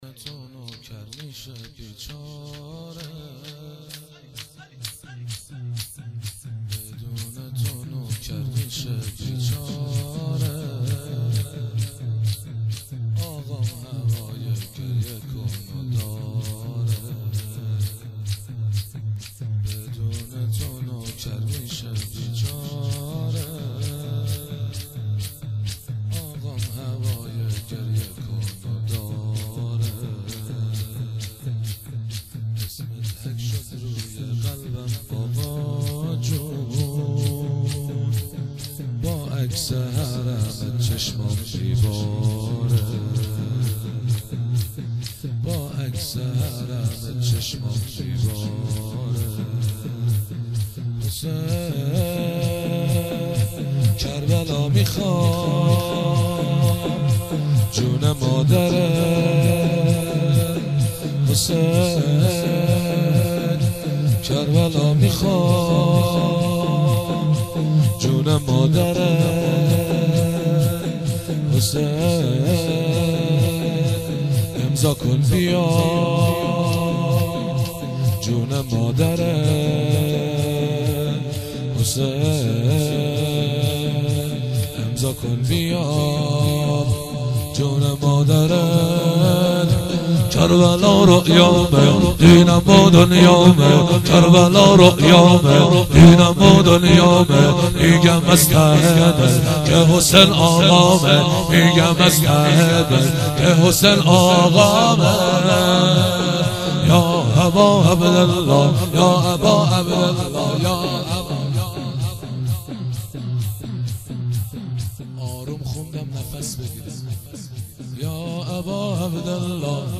شور
شهادت امام صادق۱۳۹۸